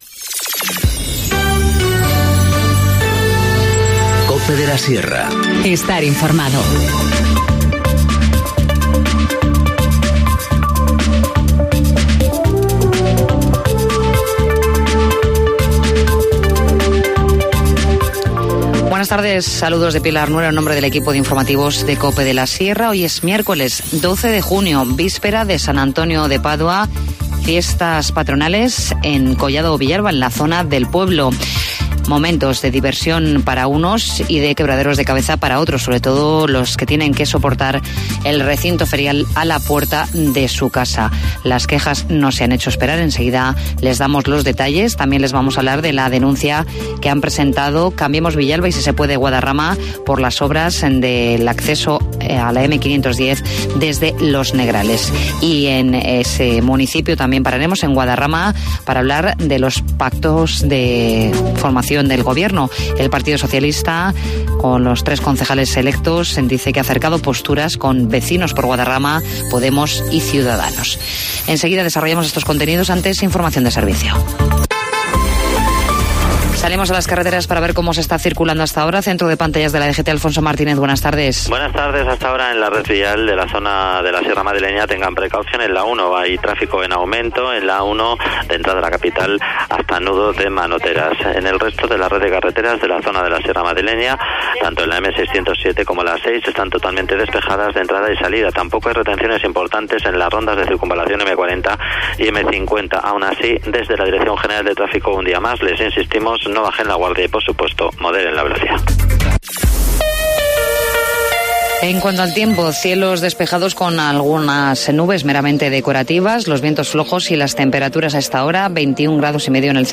Informativo Mediodía 12 junio 14:20h